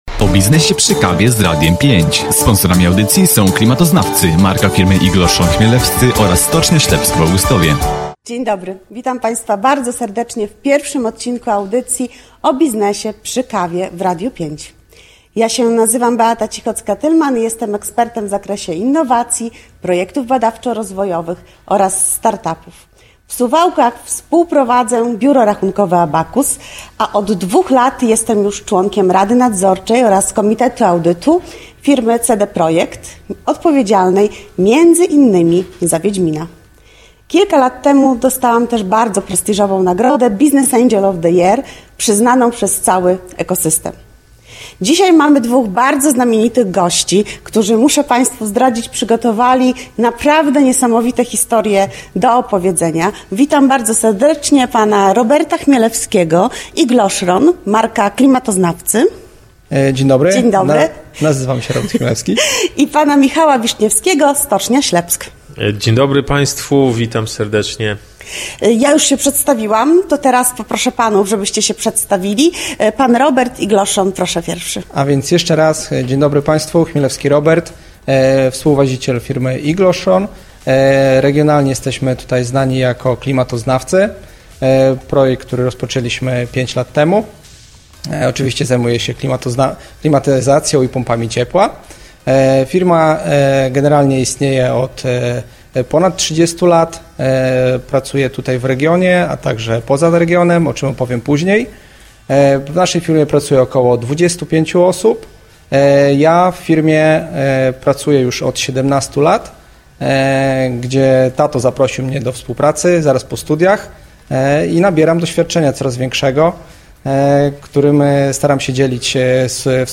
W czwartek 9.04 na antenie Radia 5 ruszyła nowa audycja ” O biznesie przy kawie”.